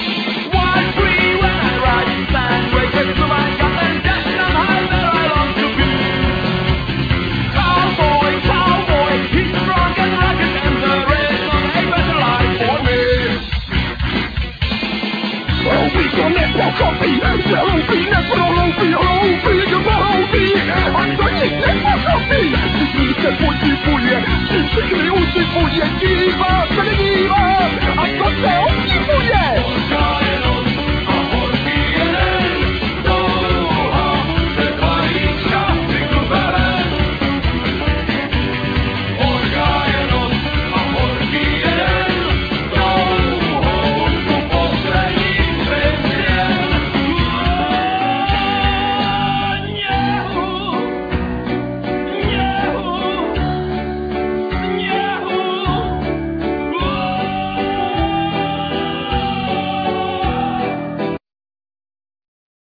Guitar,Keyboards,Tambarine,Vocal
Tenor,sazophone,Vocal
Drums
Trumpet
Accordion
Tuba